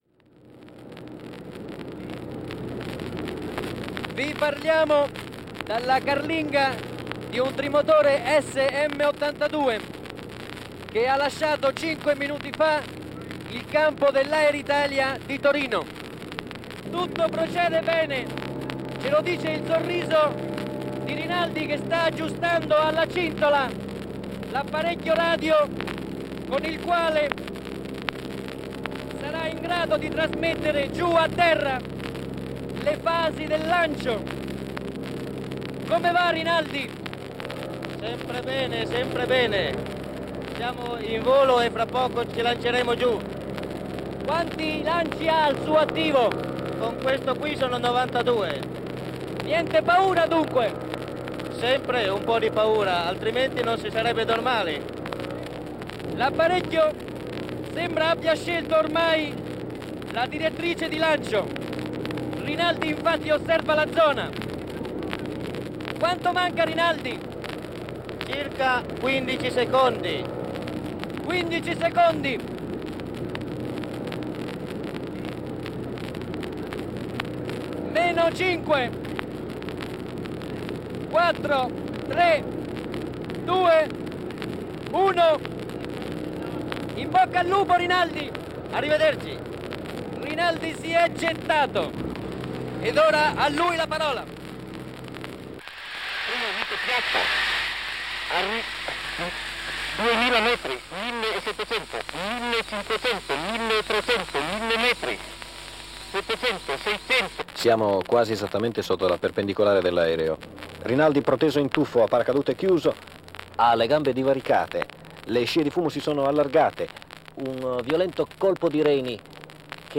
Radiocronaca di una discesa col paracadute (1955)